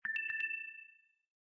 LowBattery_EAR.ogg